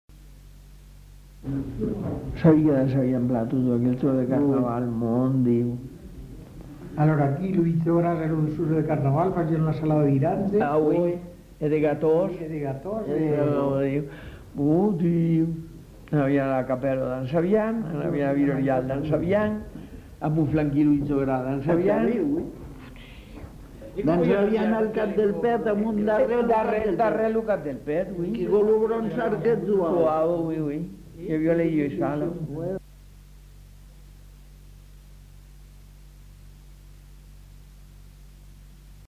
Aire culturelle : Haut-Agenais
Genre : témoignage thématique